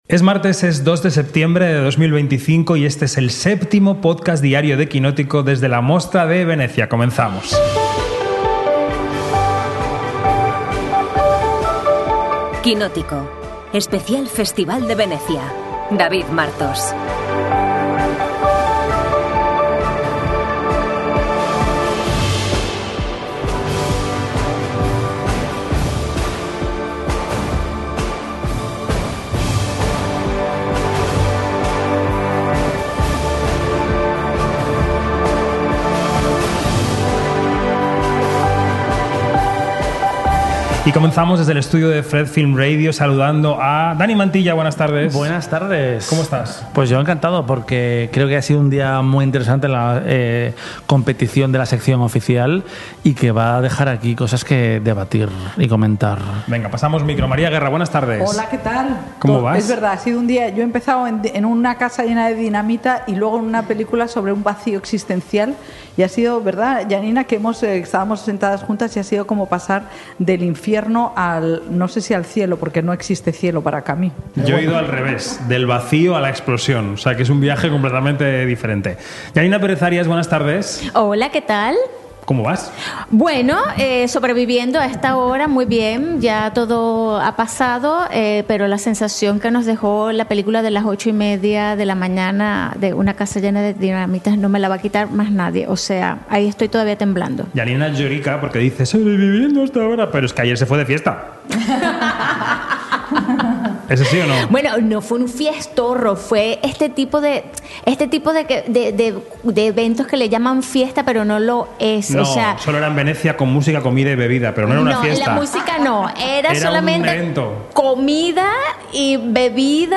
Desde la Mostra de Venecia, ¡todo lo que necesitas saber, todos los días!